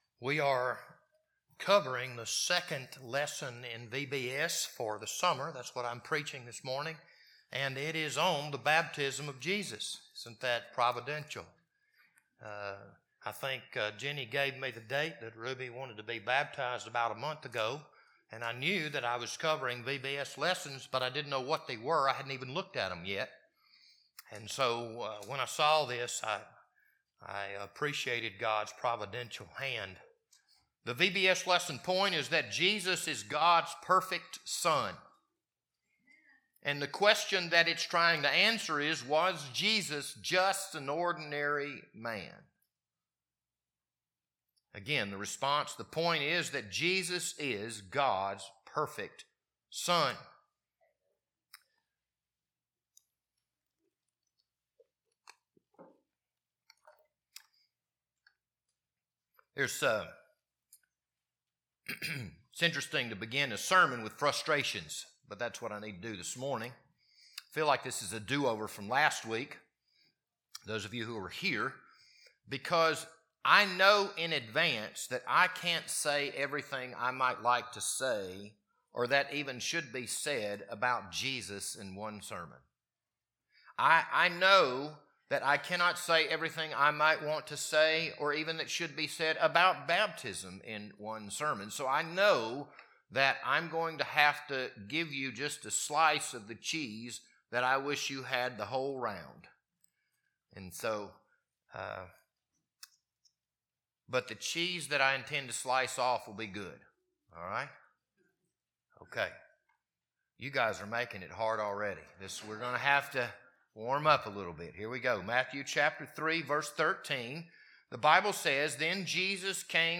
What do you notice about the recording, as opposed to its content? This Sunday morning sermon was recorded on April 26th, 2026.